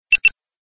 alert.oga